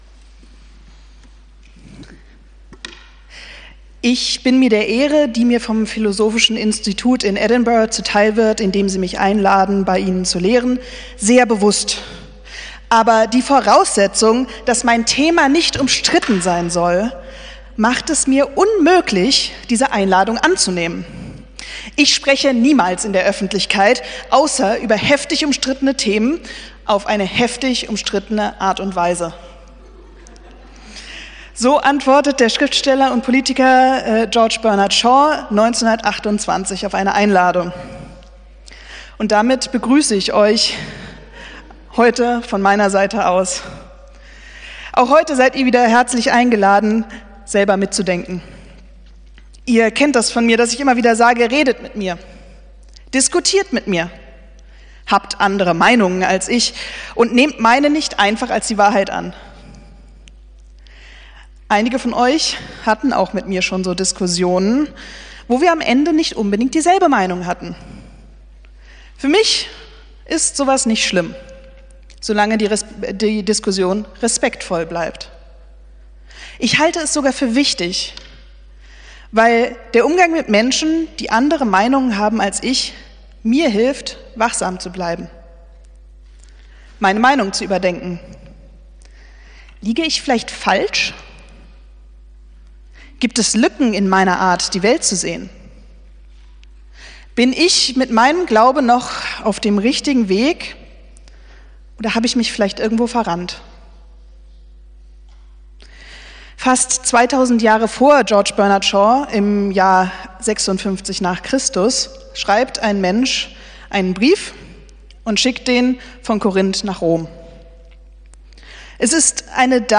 Predigt vom 17.11.2024